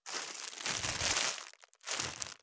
2025年3月1日 / 最終更新日時 : 2025年3月1日 cross 効果音
621コンビニ袋,ゴミ袋,スーパーの袋,袋,買い出しの音,ゴミ出しの音,袋を運ぶ音,